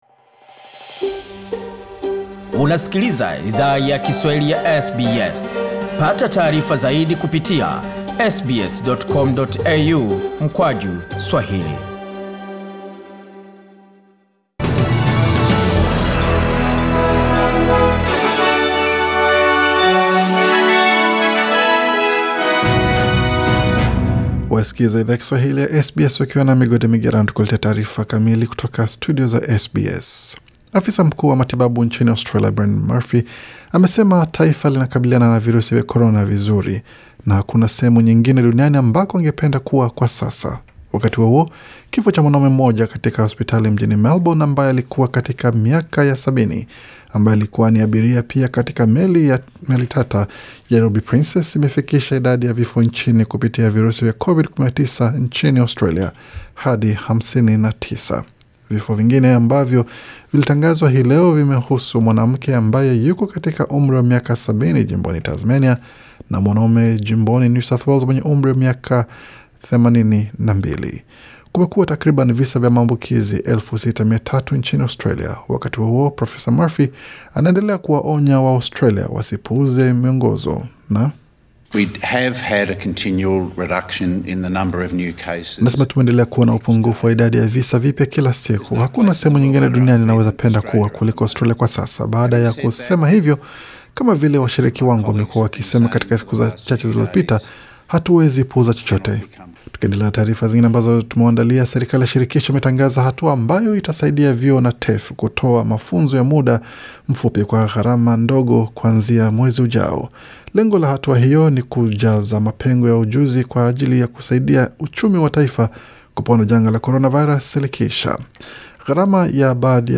Taariza za habari: Waumini washiriki katika ibada za pasaka mtandaoni